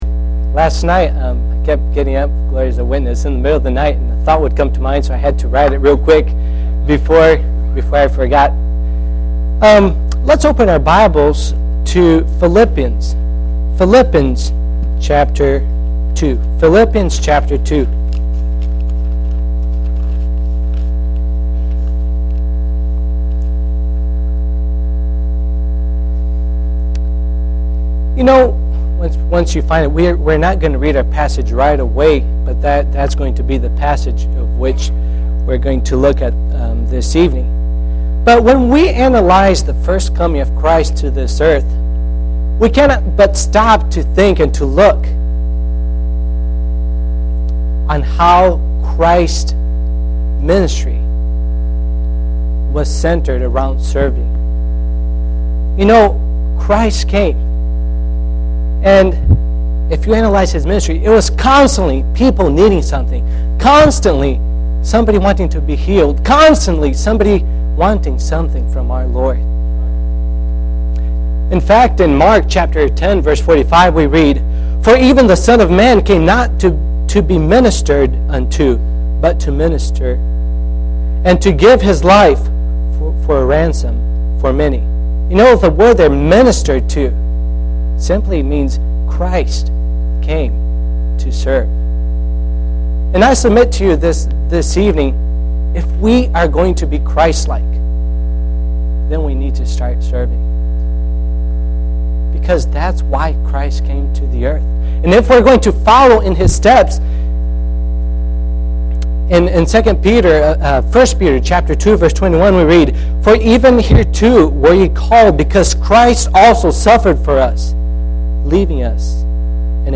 Philippians 2:2-7 Service Type: Sunday PM Bible Text